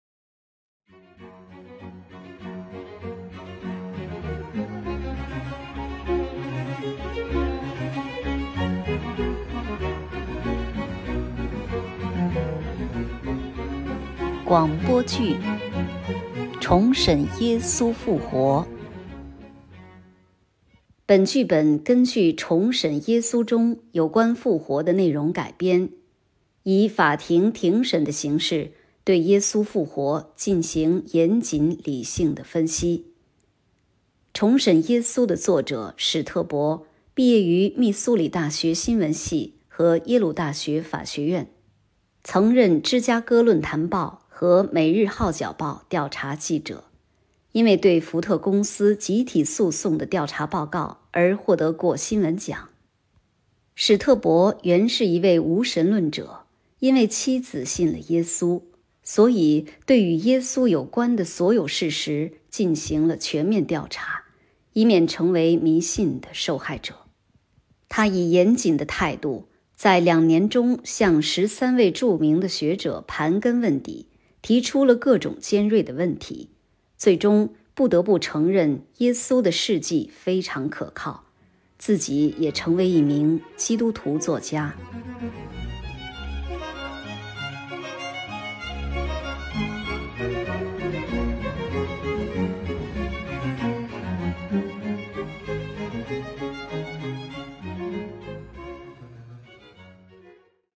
《重审耶稣复活》广播剧